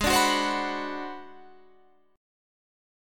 Listen to G#7#9b5 strummed